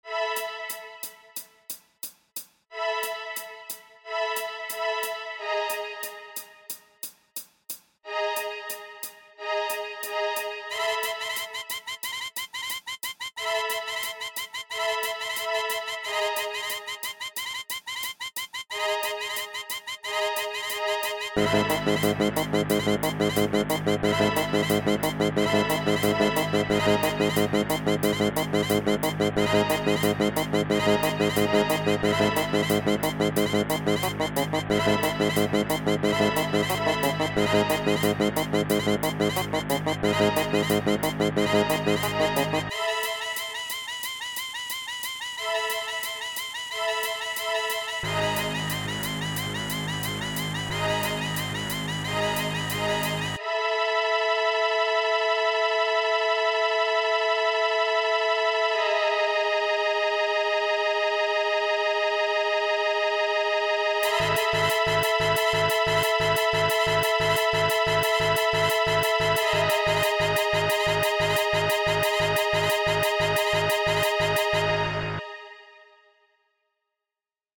I tried making a horror piece.